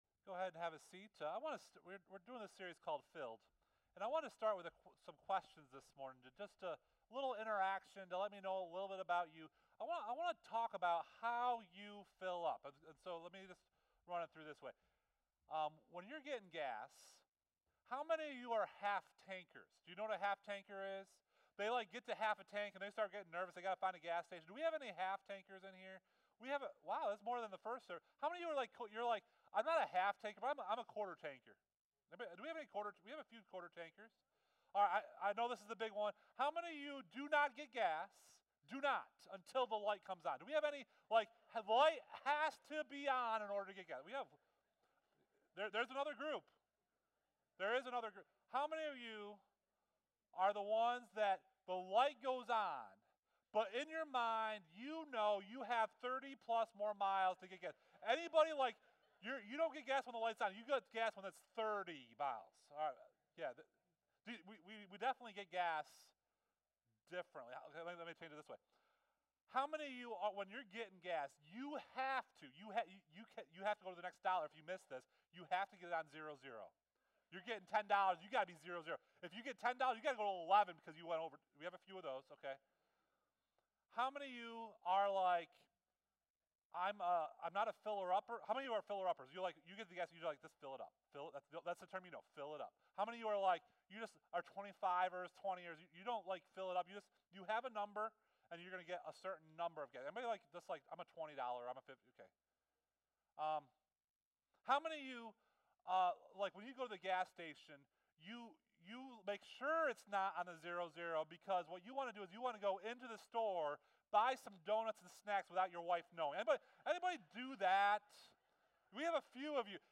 SERMON: 8/27/2017 FILLED (Week 2)